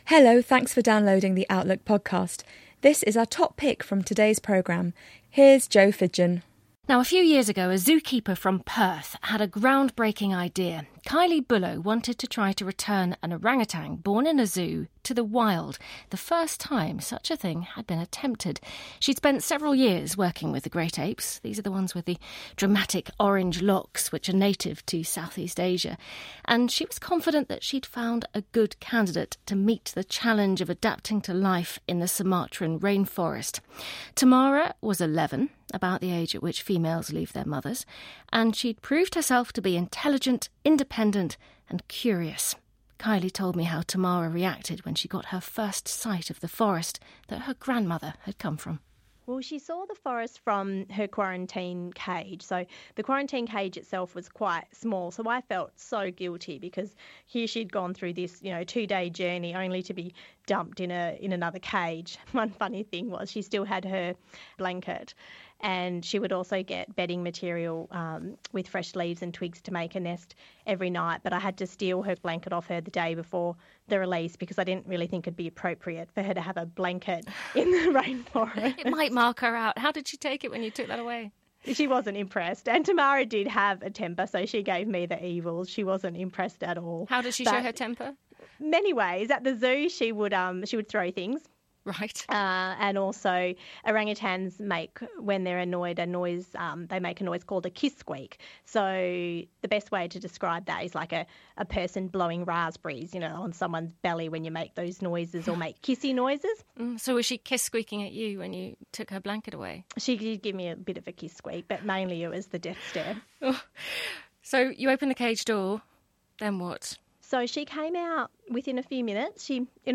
Listen to Interview I loved this orangutan like a sister A few years ago, a zoo keeper from Perth had a ground-breaking idea.